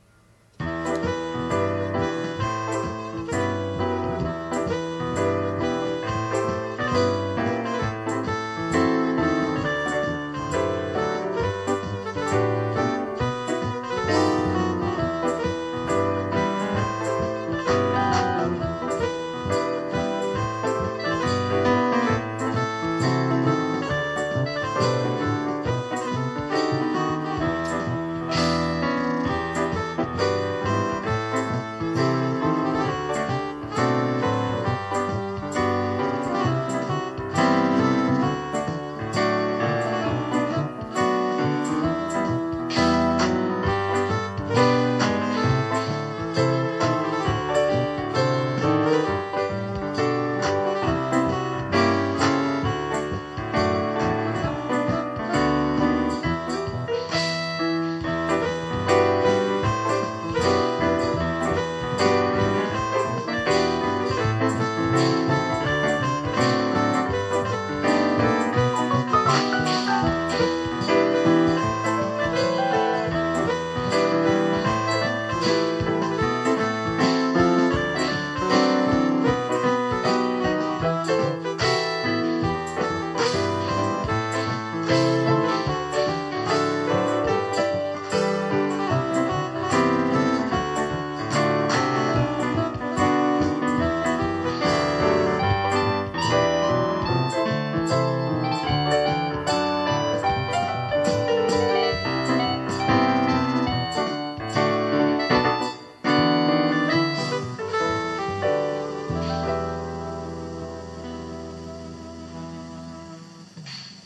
ברור ונקי